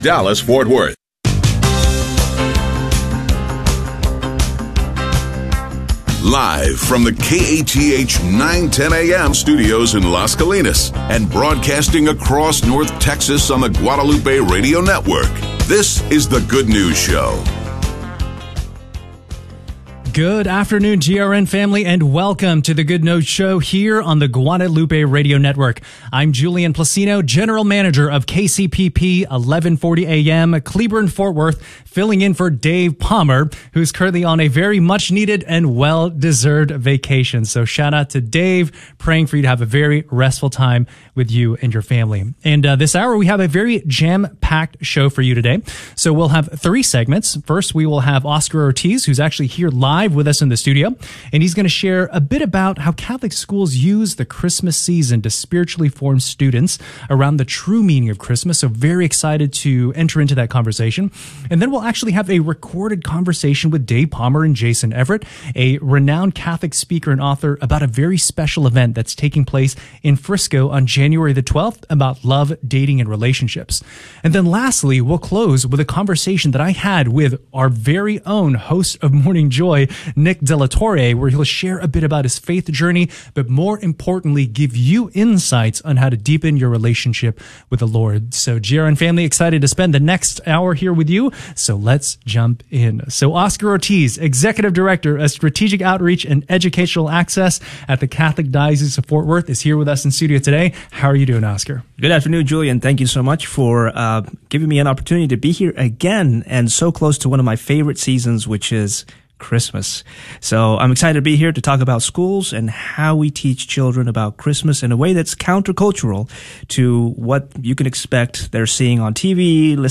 One hour of solid, Catholic conversation for your Monday afternoon. Focuses on issues pertinent to North Texas Catholics.